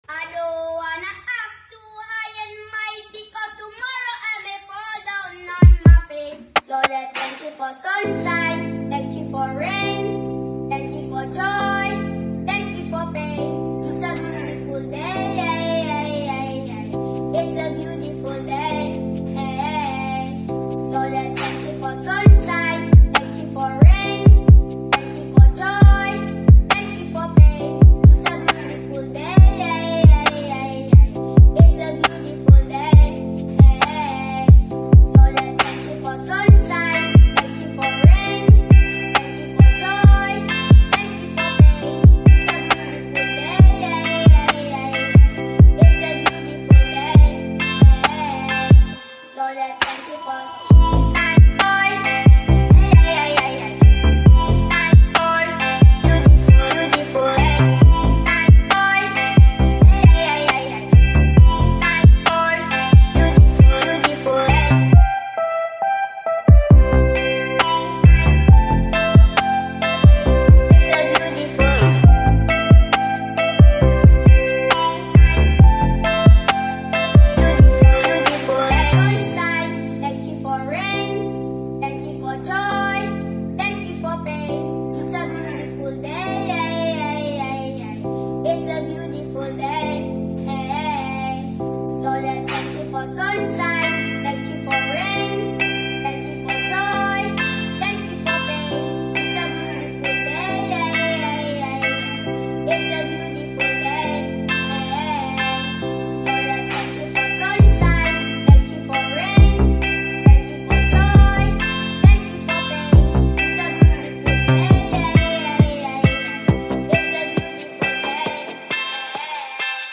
catchy tune